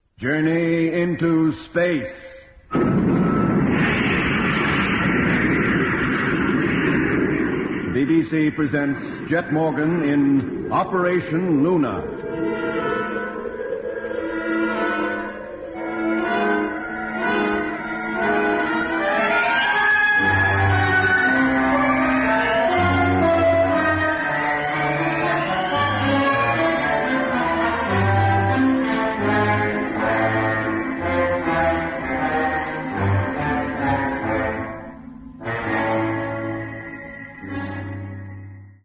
Genre: SF-ruimtevaart